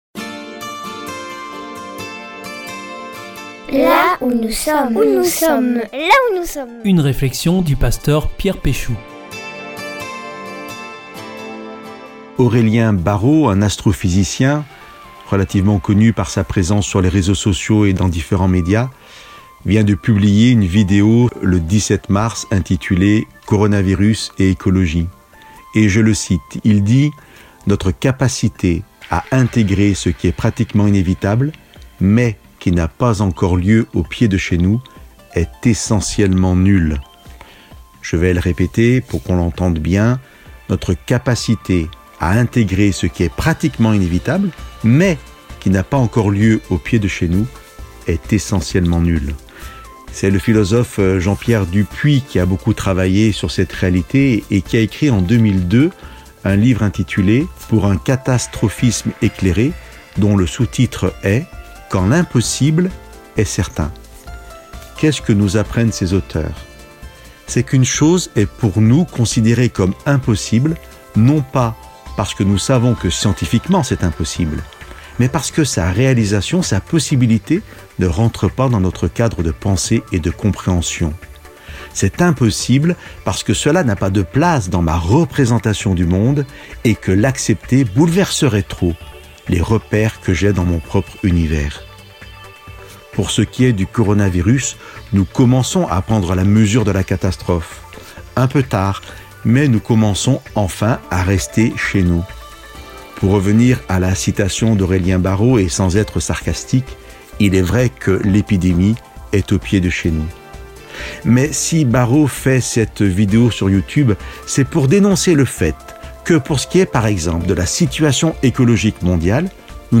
depuis son téléphone, confiné à Marseille